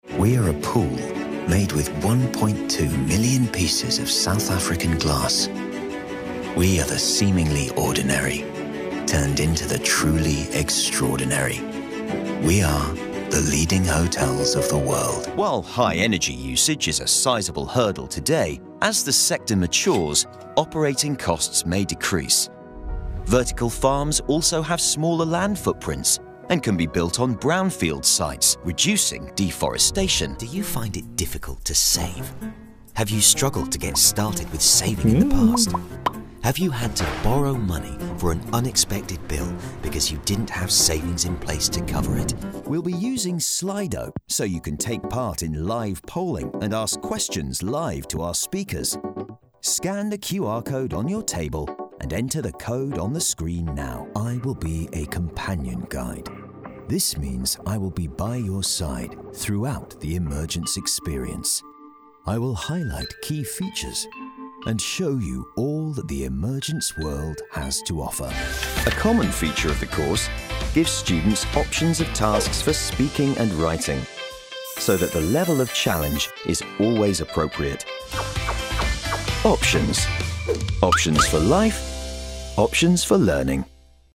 Male
Assured, Authoritative, Confident, Corporate, Deep, Engaging, Gravitas, Posh, Reassuring, Smooth, Warm, Witty
Microphone: Sontronics Aria Cardioid Condenser Valve Mic, Shure SM7B